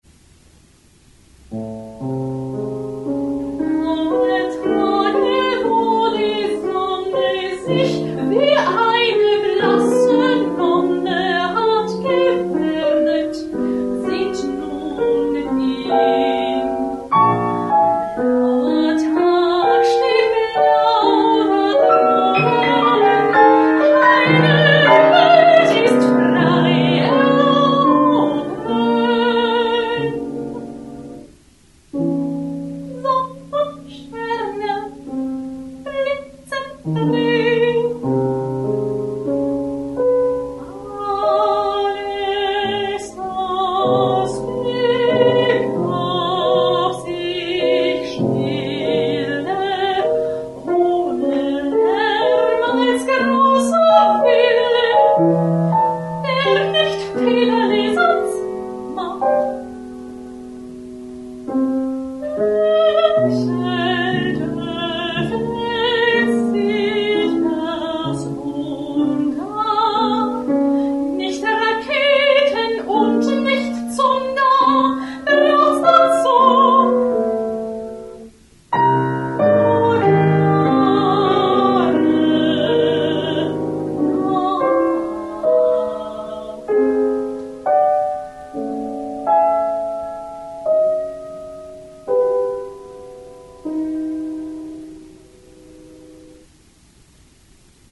für mittlere Stimme und Klavier, 8'
Umfang der Stimme: a – f´´